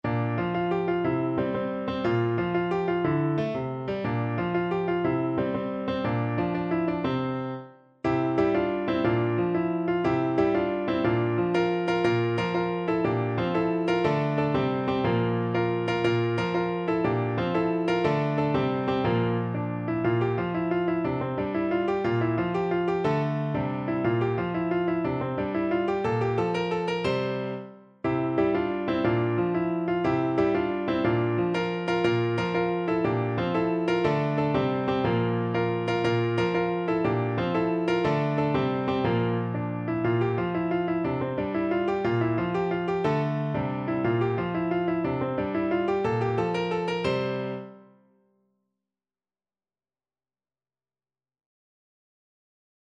Play (or use space bar on your keyboard) Pause Music Playalong - Piano Accompaniment Playalong Band Accompaniment not yet available transpose reset tempo print settings full screen
6/8 (View more 6/8 Music)
F major (Sounding Pitch) (View more F major Music for Trombone )
With energy .=c.120
Classical (View more Classical Trombone Music)